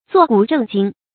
作古正經 注音： ㄗㄨㄛˋ ㄍㄨˇ ㄓㄥˋ ㄐㄧㄥ 讀音讀法： 意思解釋： 猶言一本正經。